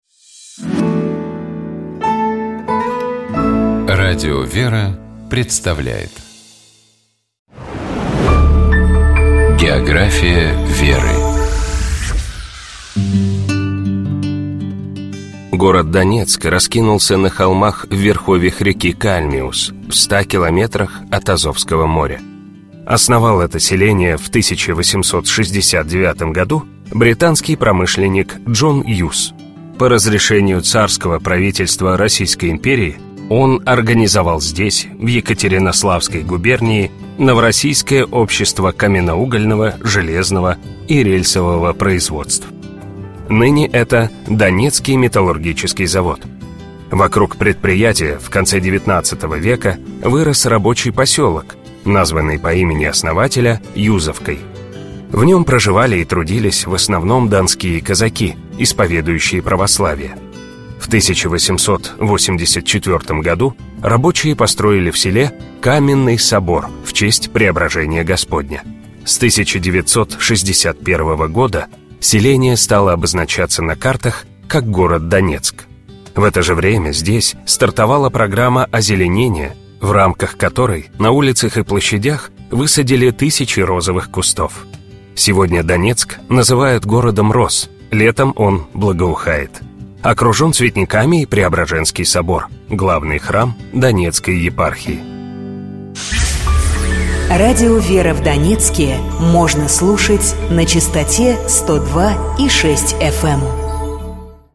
Комментирует епископ Переславский и Угличский Феоктист.